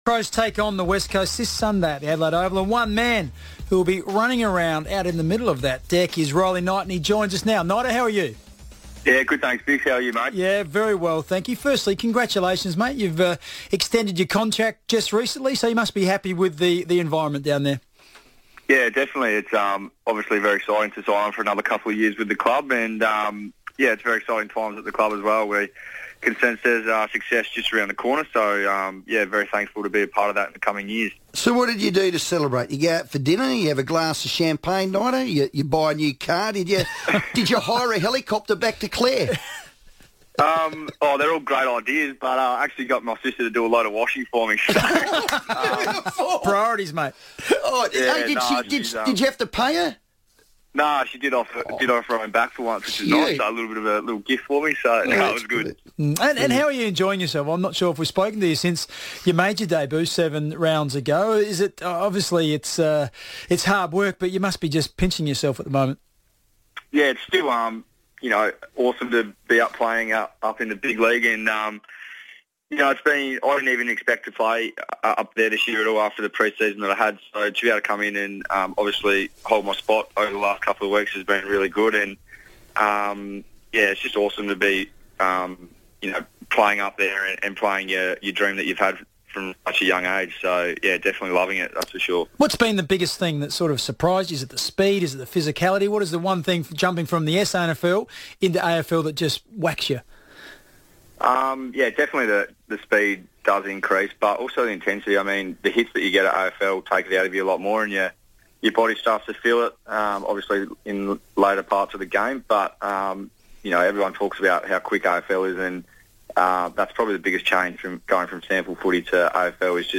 Newly re-signed Crow Riley Knight spoke on the FIVEaa Sports Show ahead of Adelaide's big clash with West Coast on Sunday afternoon